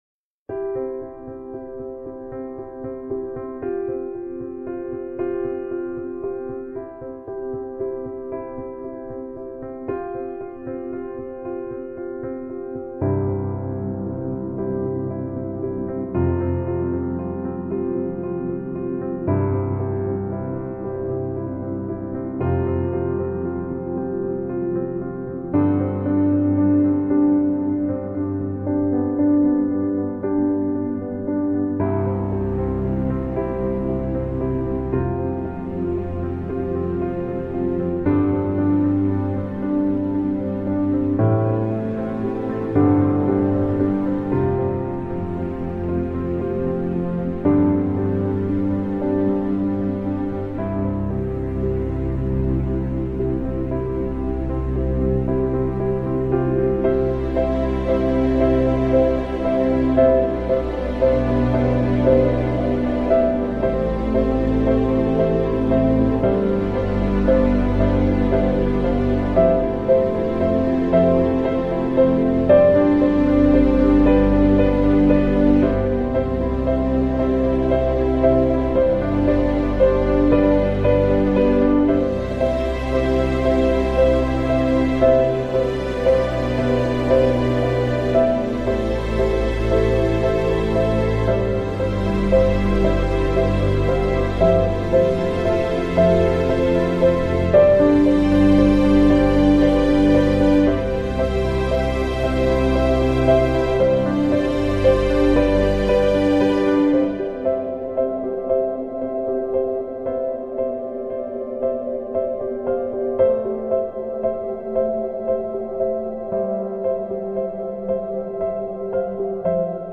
AUDIO: Music for lyrics below...